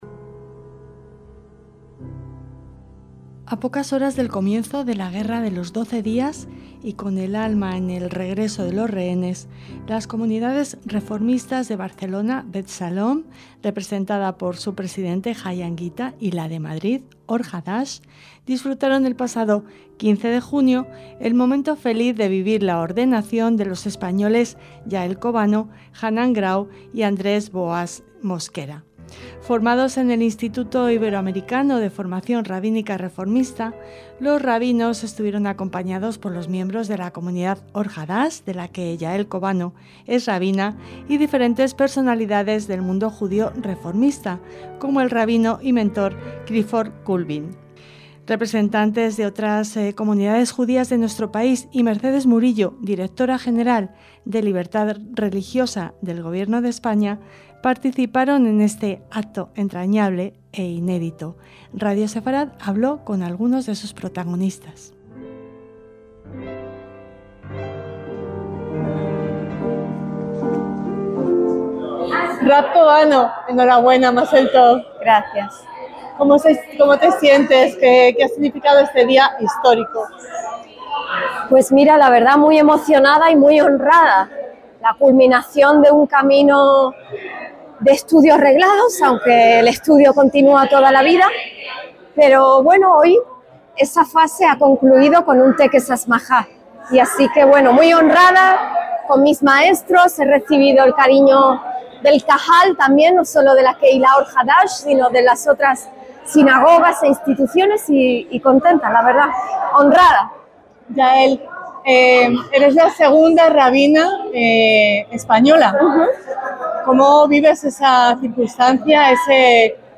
EL REPORTAJE